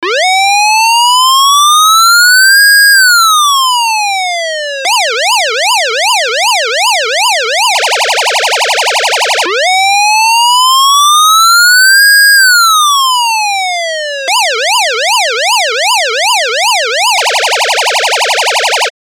Wail, Yelp, Piercer, Combined
This combination of all three siren sounds is mostly heard when an emergency vehicle runs into busy traffic in a street or town. They use all three in sequence to maximise their chances of being heard.
siren-emergency-wail-yelp-piercer-1.mp3